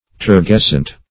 turgescent - definition of turgescent - synonyms, pronunciation, spelling from Free Dictionary
Search Result for " turgescent" : The Collaborative International Dictionary of English v.0.48: Turgescent \Tur*ges"cent\, a. [L. turgescens, -entis, p. pr. of turgescere: cf. F. turgescent.
turgescent.mp3